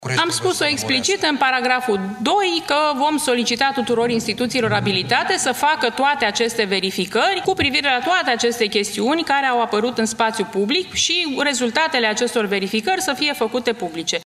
Declarațiile au fost făcute la conferința de presă extraordinară organizată de Curtea de Apel București, desfășurată după dezvăluirile din reportajul-documentar realizat de Recorder despre sistemul de justiție.